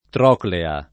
[ tr 0 klea ]